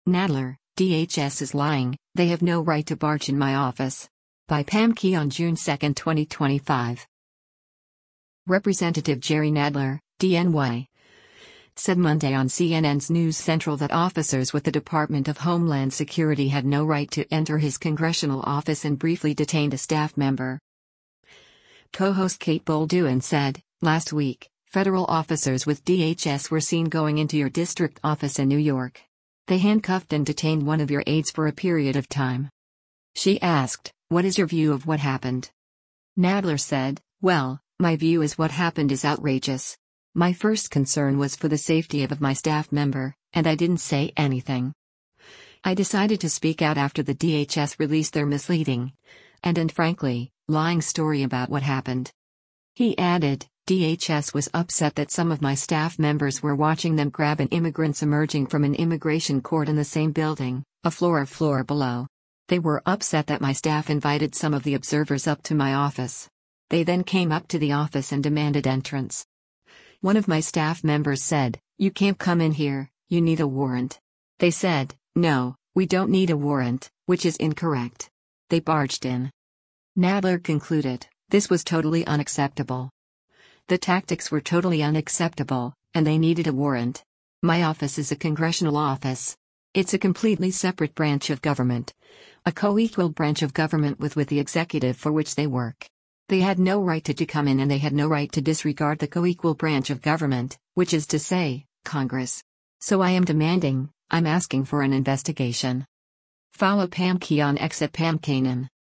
Representative Jerry Nadler (D-NY) said Monday on CNN’s “News Central” that officers with the Department of Homeland Security had no right to enter his congressional office and briefly detained a staff member.